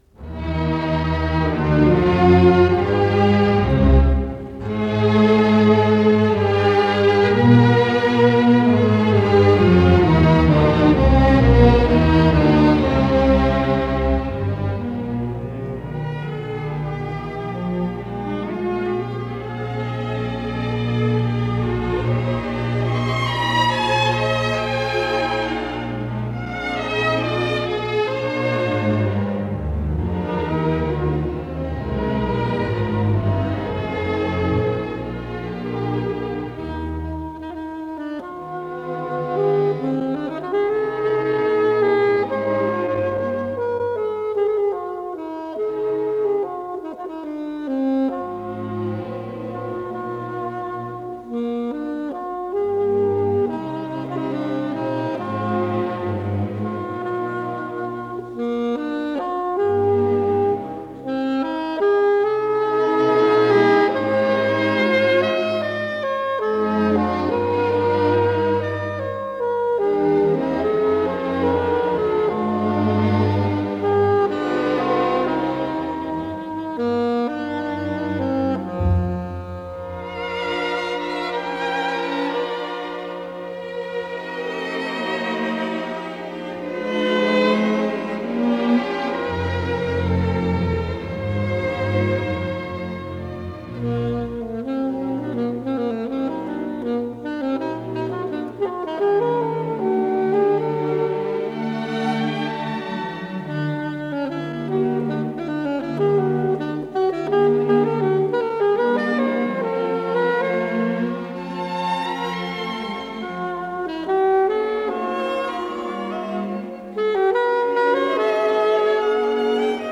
П-0195 — Концерт для альтового саксофона и струнного оркестра (одночастный) — Ретро-архив Аудио
Ми бемоль мажор